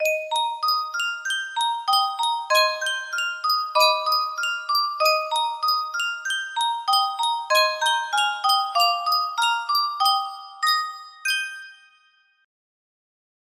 Sankyo Music Box - JR-SH1-1 KHG music box melody
Full range 60